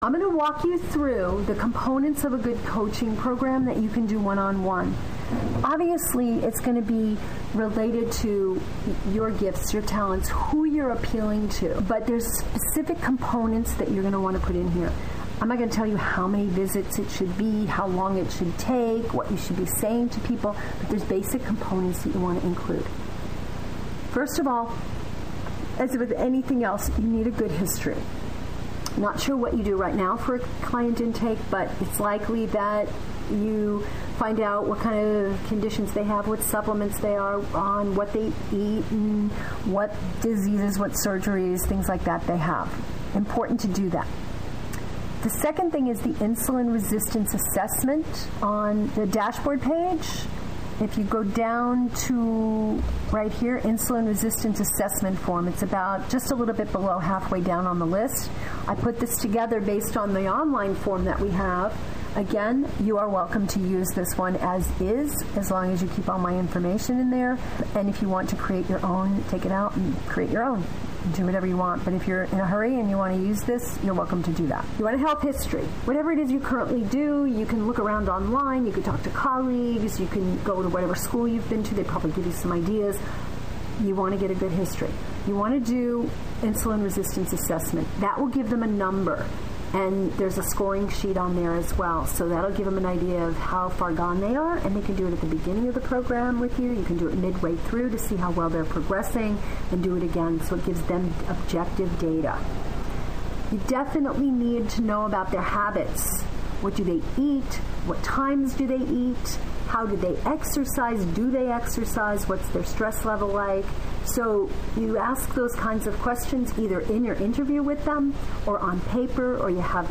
Note: This presentation is an excerpt from the IRSPT 2012 virtual seminar.